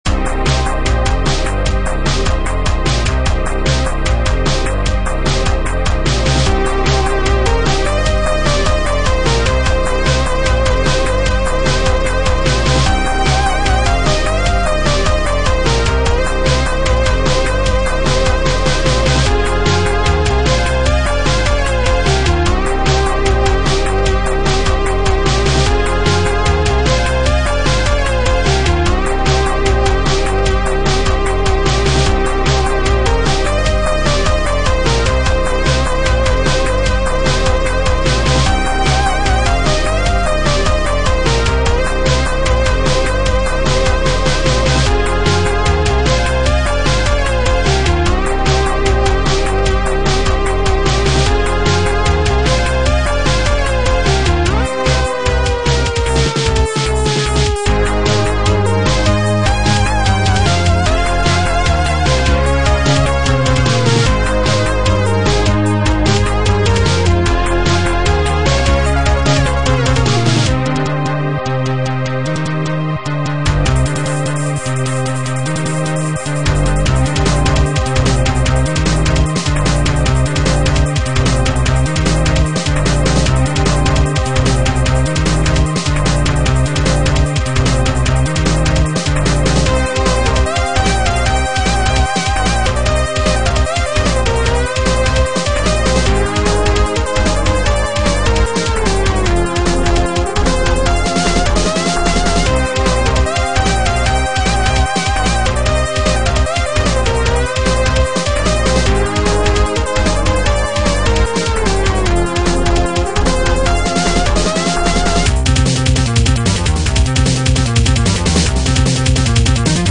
Digital title music
This game features digital title music